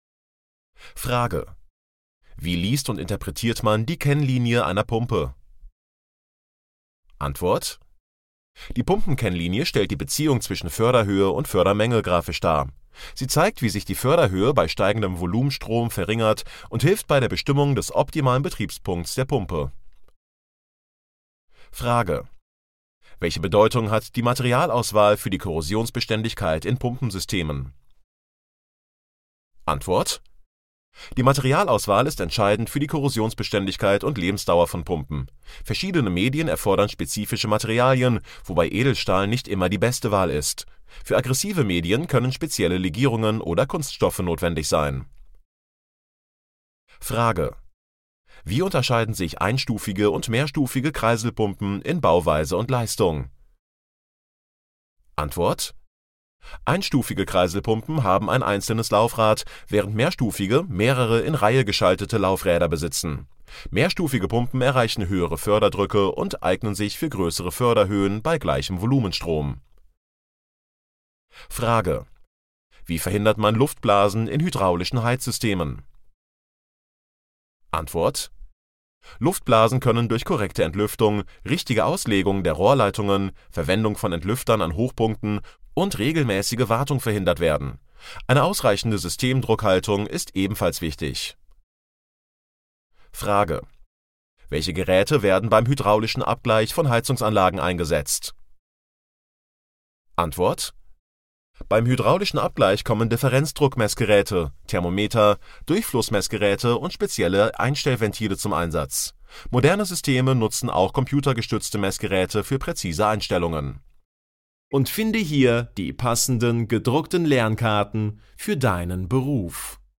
MP3 Hörbuch Installateur- und Heizungsbauermeister - Download